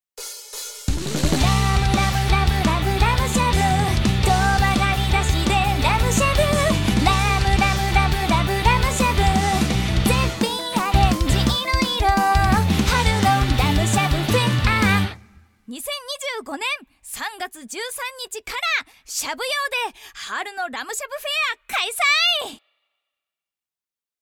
楽曲CM